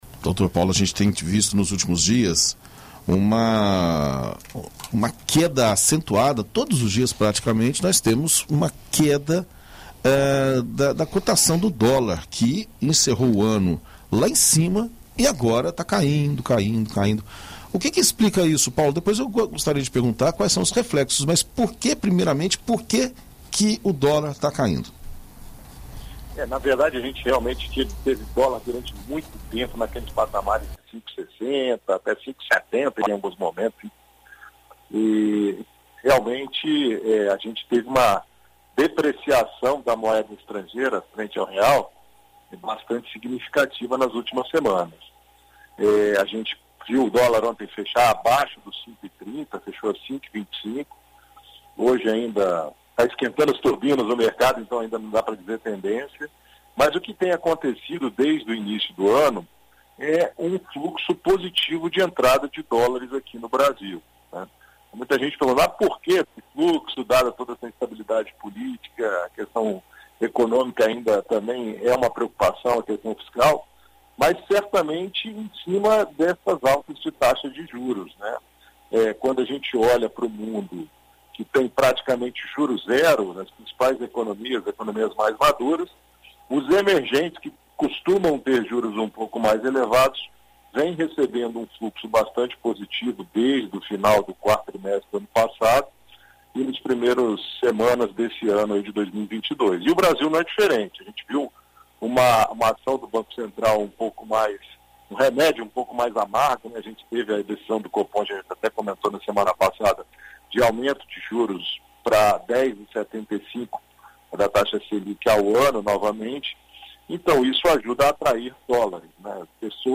Na coluna Seu Dinheiro desta terça-feira (08), na BandNews FM Espírito Santo, o economista e especialista em mercado financeiro